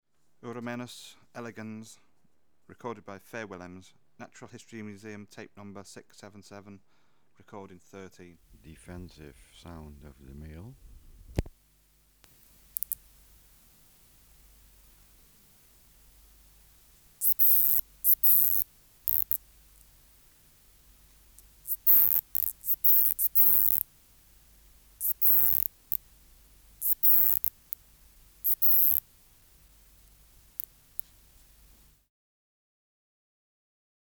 568:38 Uromenus elegans (677r13) | BioAcoustica
Air Movement: Nil
Isolated male, out of cage, being handled
Microphone & Power Supply: AKG D202E (LF circuit off) Distance from Subject (cm): 4